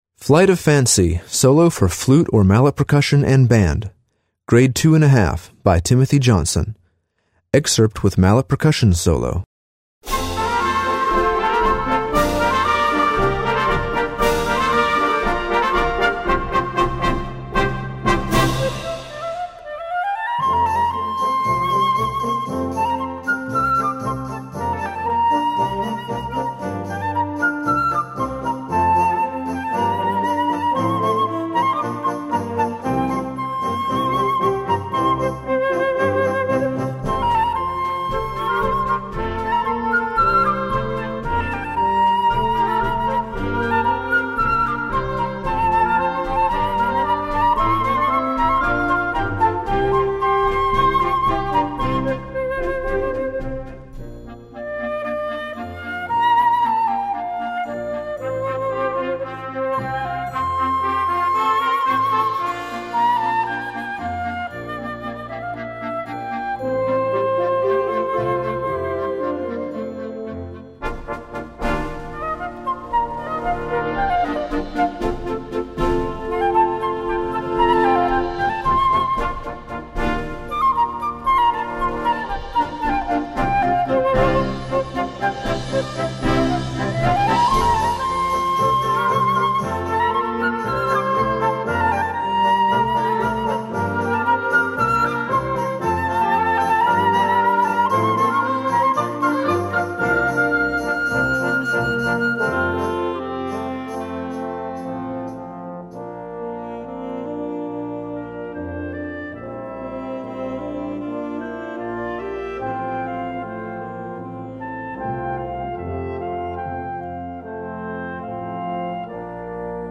Voicing: Flute Solo w/ Band